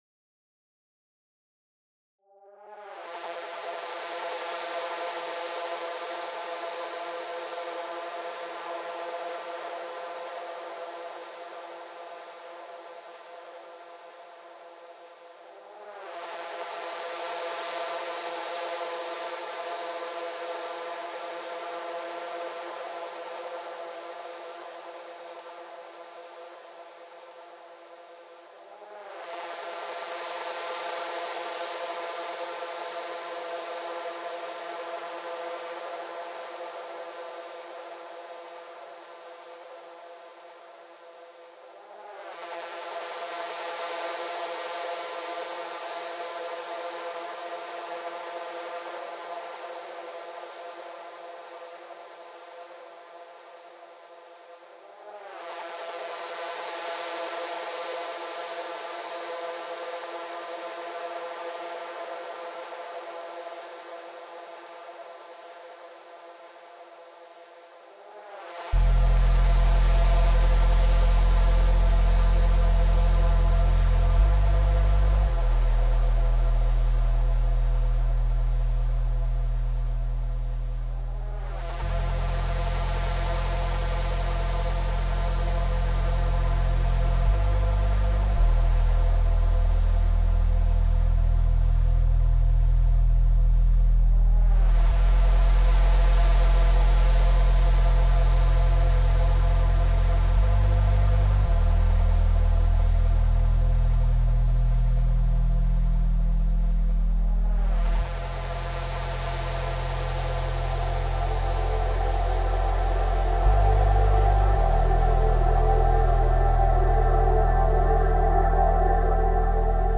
ambient soundtrack